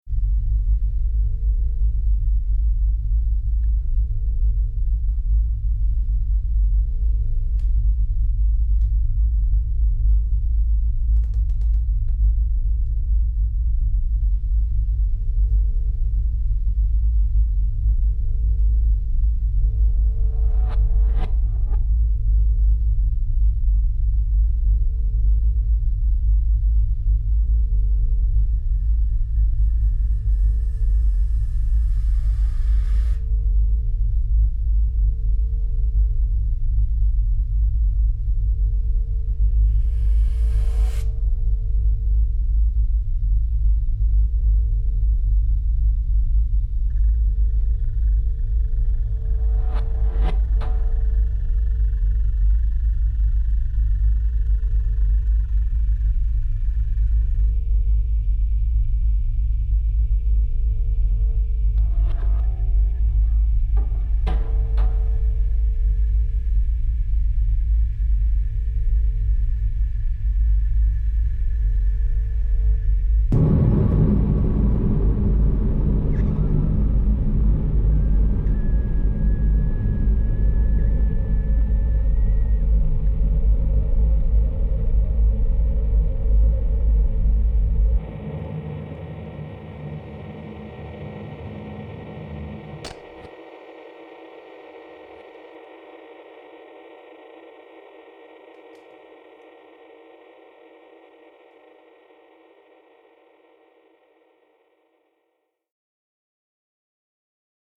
electronics
trumpet